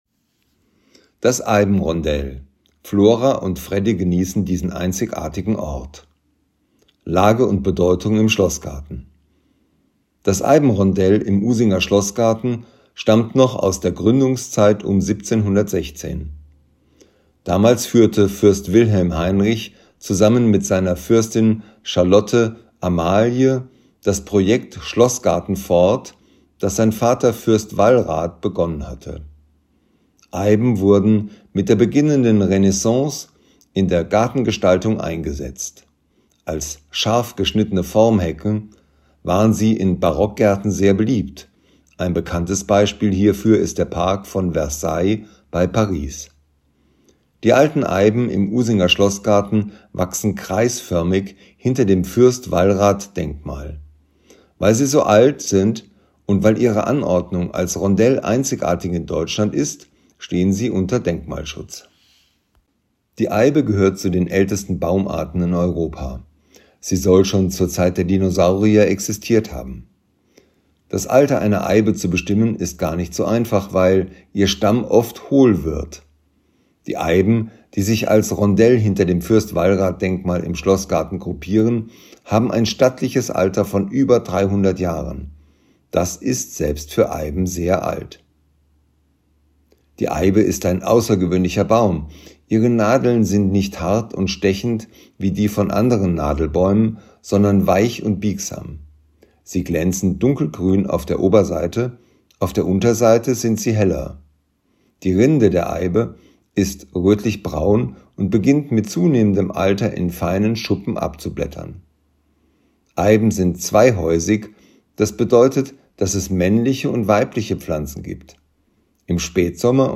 Wer nicht alle Schilder an den Stationen im Schlossgarten lesen möchte oder kann, kann sie sich hier einfach vorlesen lassen.